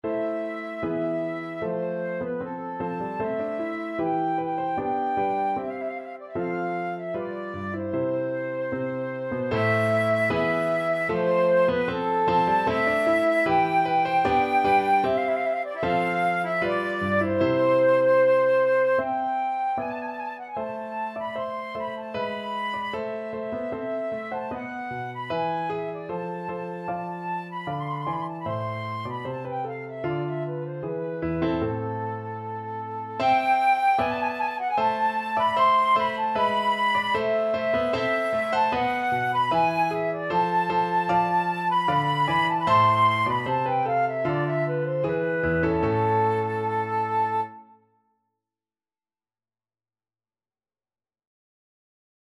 Flute
2/2 (View more 2/2 Music)
A minor (Sounding Pitch) (View more A minor Music for Flute )
Classical (View more Classical Flute Music)